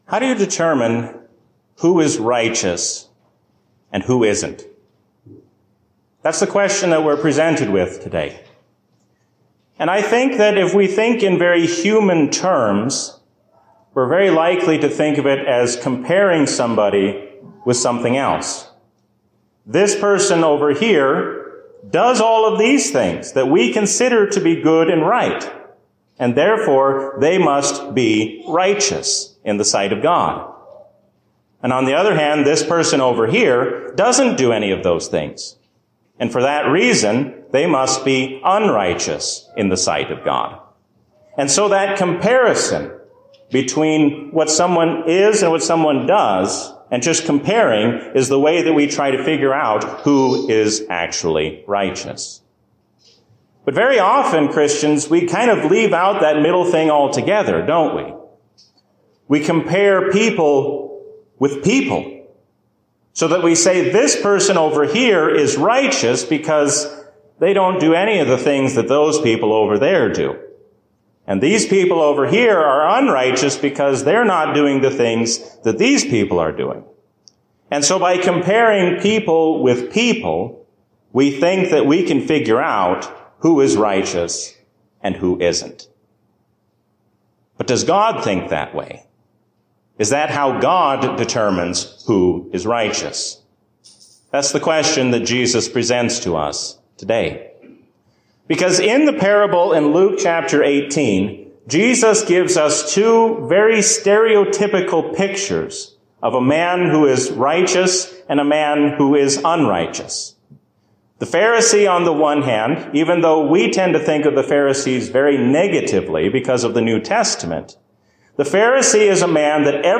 A sermon from the season "Trinity 2022." When we humble ourselves under the hand of God, then we have no reason to be anxious about the future.